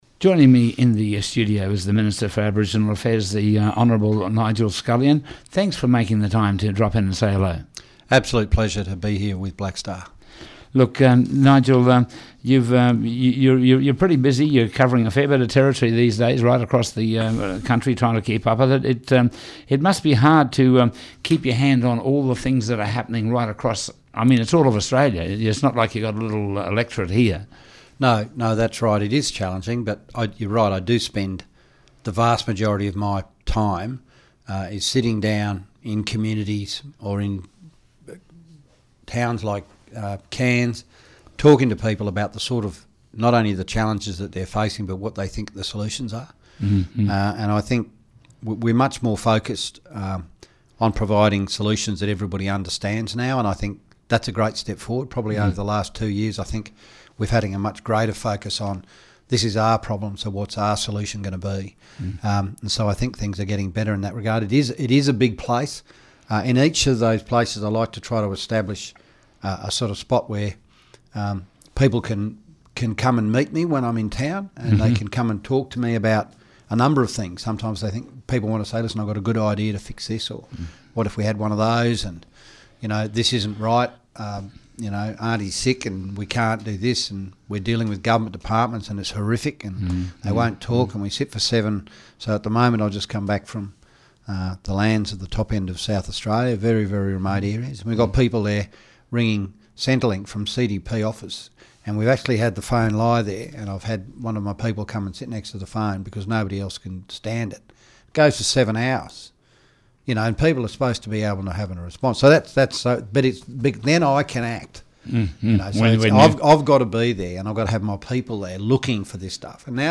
Minister for Indigenous Affairs on Black Star Radio